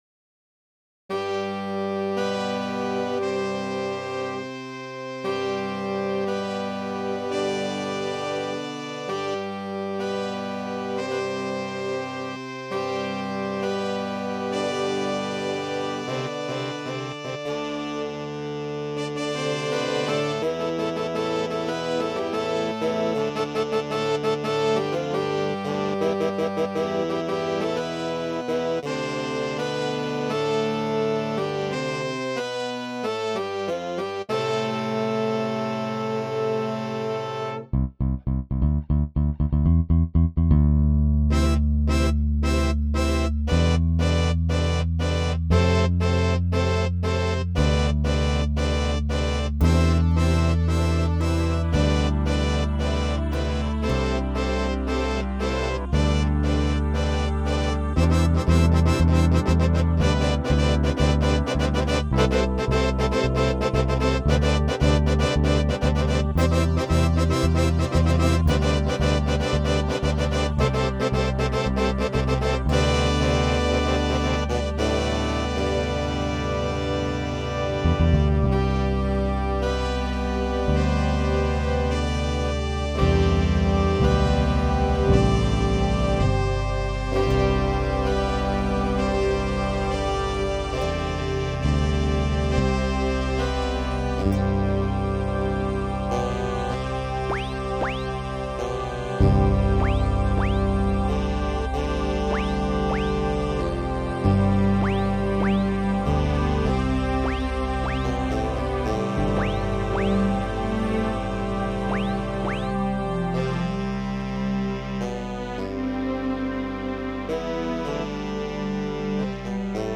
The MP3s I've included here are recent experiments using Apple's GarageBand.
Instrumentals